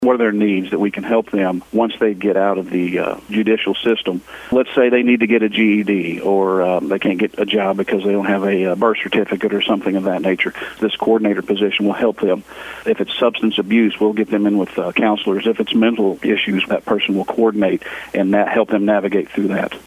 St. Francois County Sheriff Jeff Crites discusses a smooth transition from former Sheriff Dan Bullock’s administration and introduces a new Residential Substance Abuse Treatment Coordinator position.